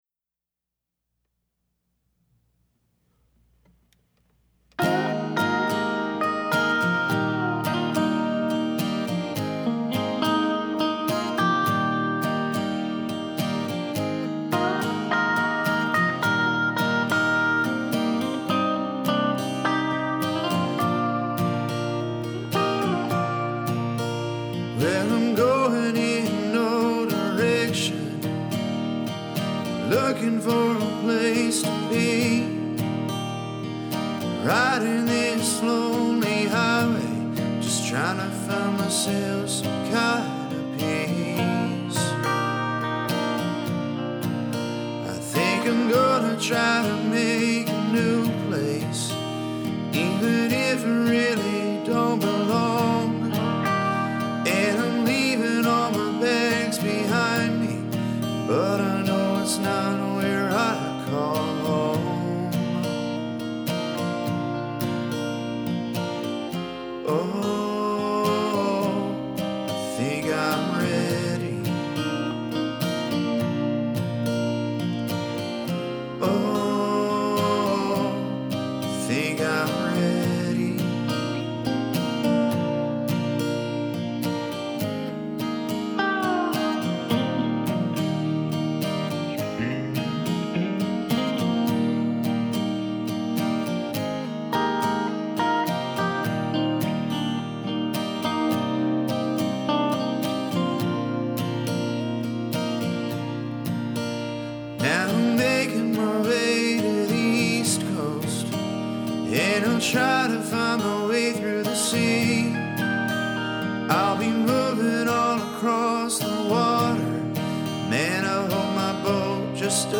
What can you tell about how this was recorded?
solo/duo acoustic project